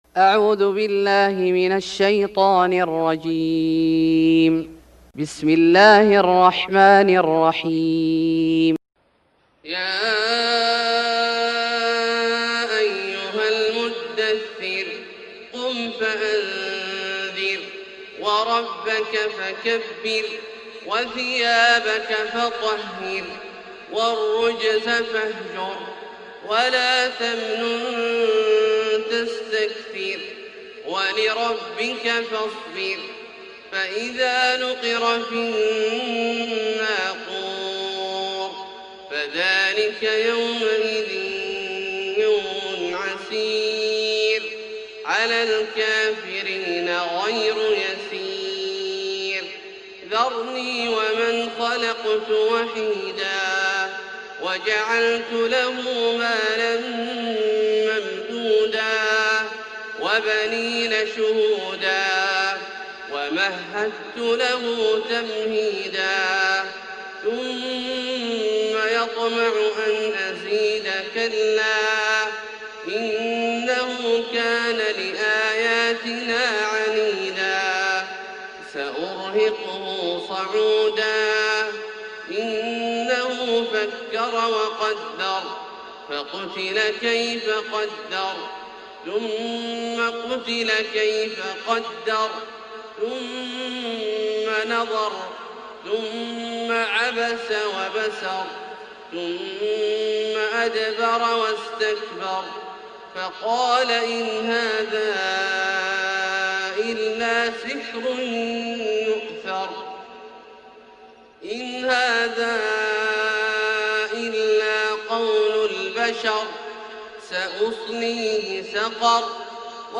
سورة المدثر Surat Al-Mudathir > مصحف الشيخ عبدالله الجهني من الحرم المكي > المصحف - تلاوات الحرمين